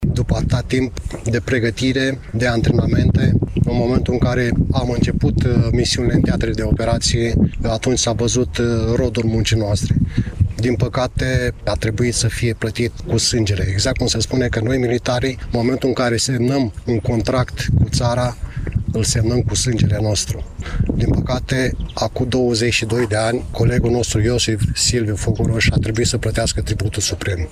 Astăzi, la Cimitirul Eterninatea din Iași a fost marcată ziua veteranilor de război și ziua armistițiului din primul Război Mondial.